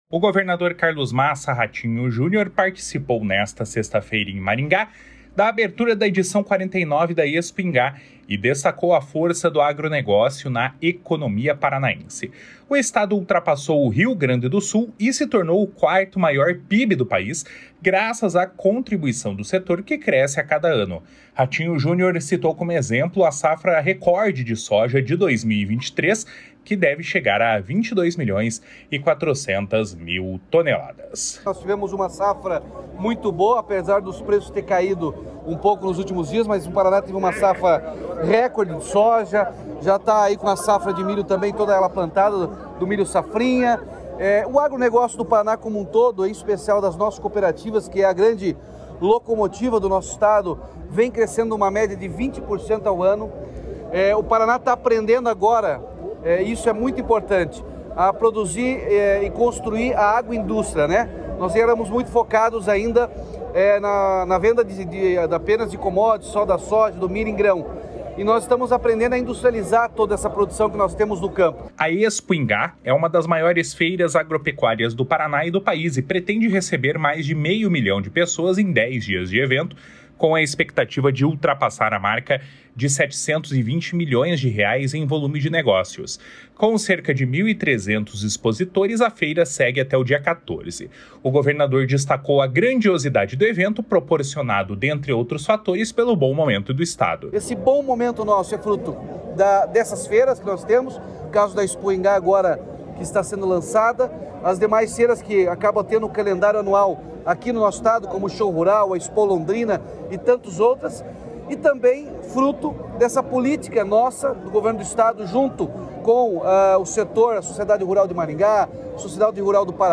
// SONORA RATINHO JUNIOR //
// SONORA NORBERTO ORTIGARA //